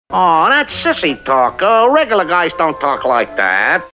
Category: Television   Right: Personal